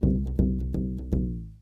Balloon Bouncing Sound
cartoon
Balloon Bouncing